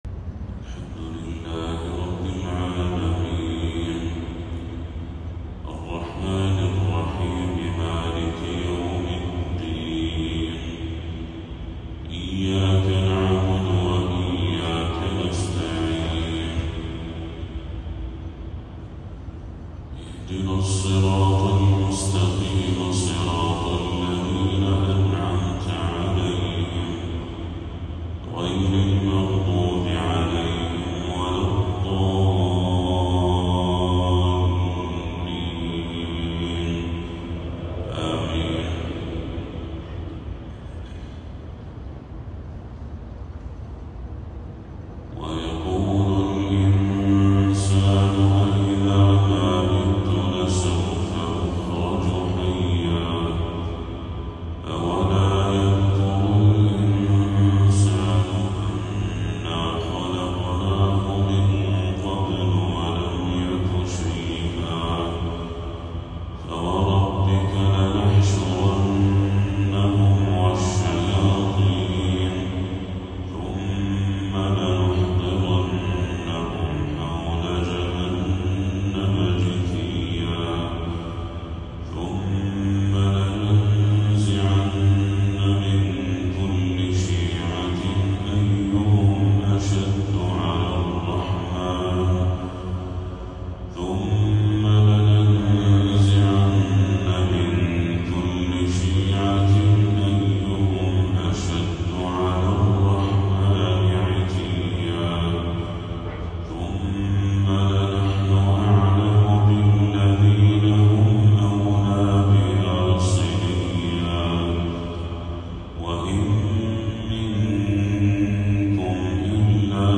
تلاوة خاشعة لخواتيم سورة مريم للشيخ بدر التركي | فجر 7 ربيع الأول 1446هـ > 1446هـ > تلاوات الشيخ بدر التركي > المزيد - تلاوات الحرمين